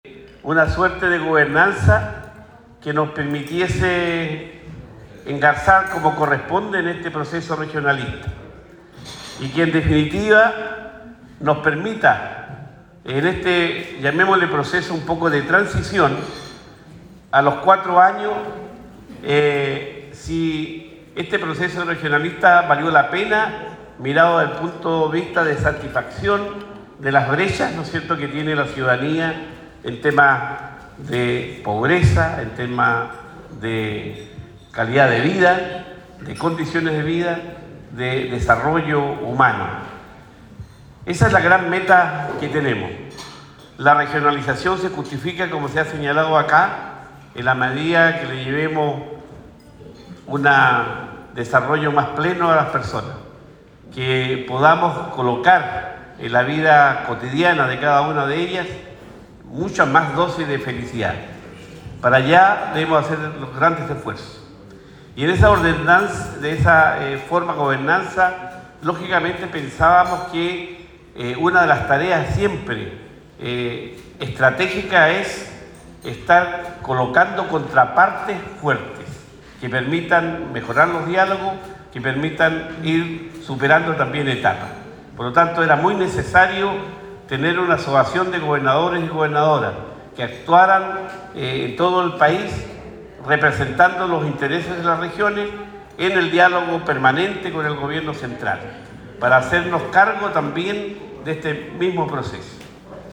Cuña_Luis-Cuvertino_Día-de-Las-Regiones.mp3